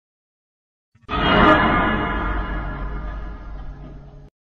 Fnaf Door Sound
FNaF-Door-Sound.mp3